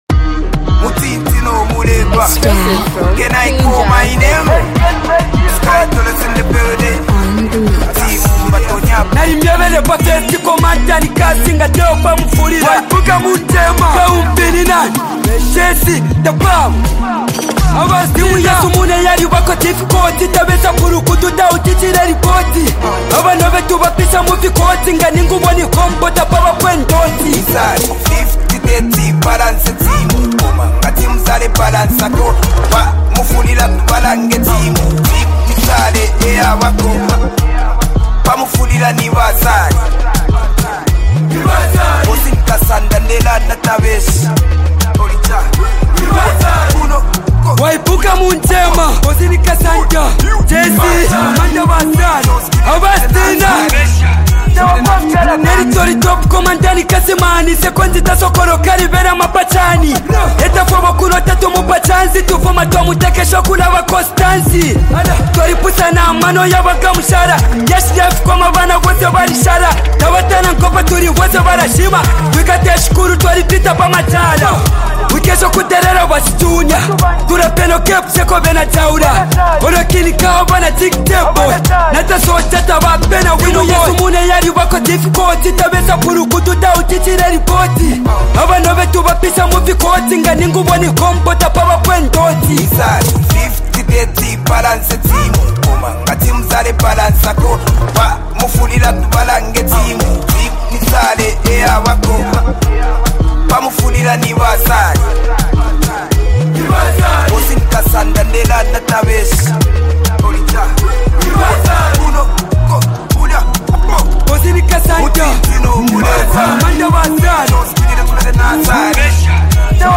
hype, street vibes, and a powerful hook with unique rap flow